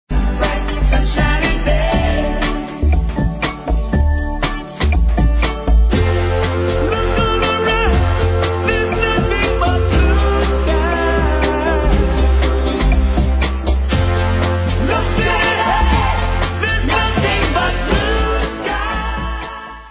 sledovat novinky v oddělení World/Reggae